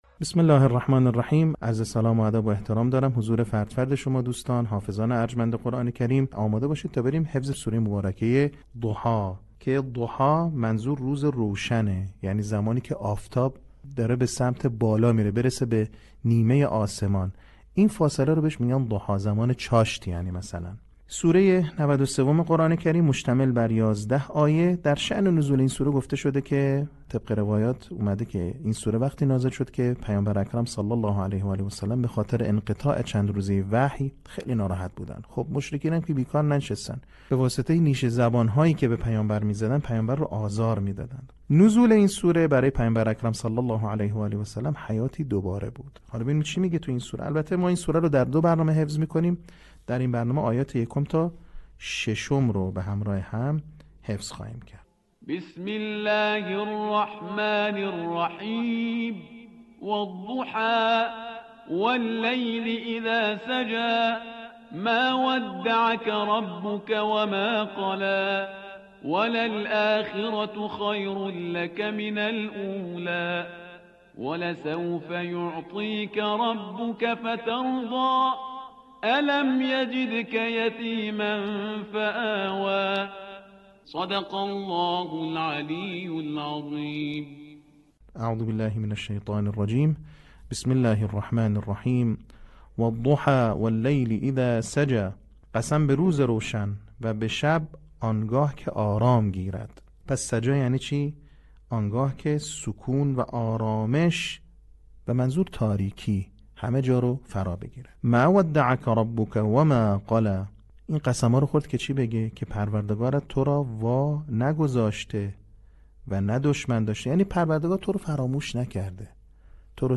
صوت | آموزش حفظ سوره ضحی